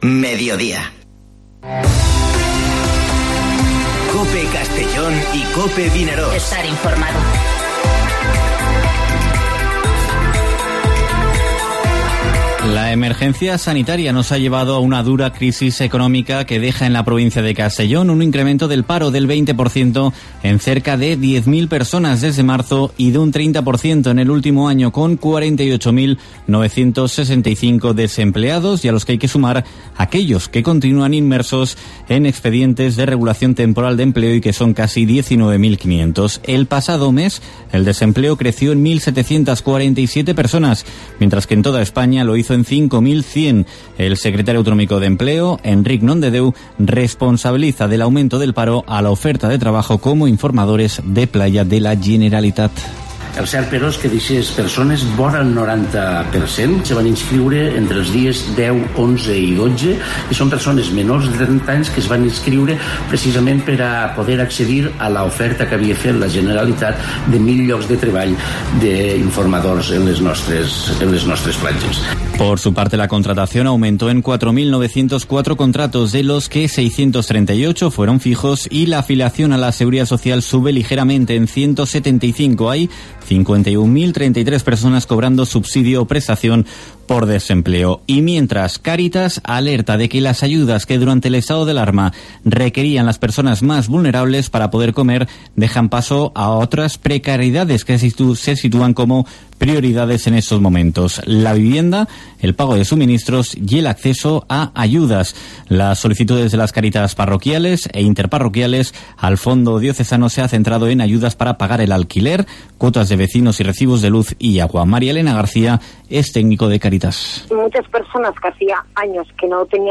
Informativo Herrera en COPE en la provincia de Castellón (02/07/2020)